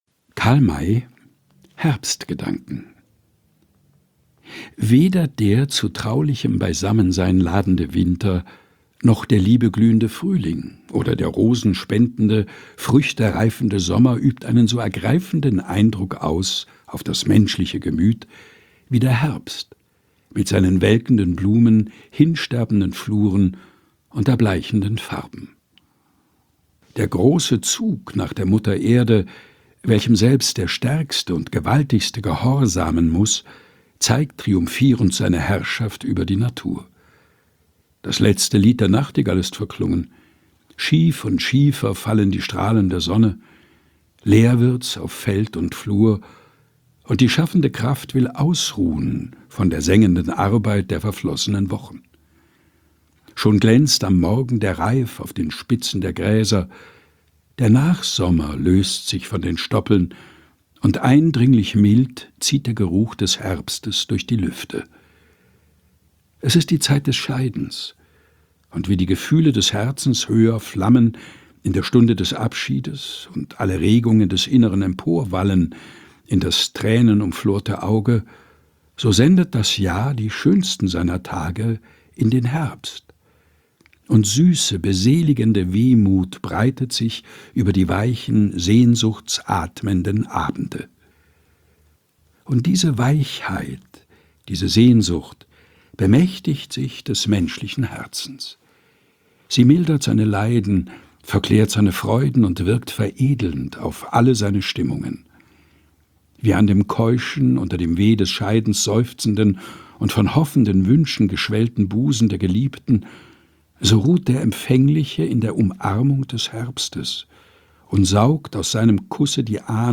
im Dachkammerstudio vorgelesen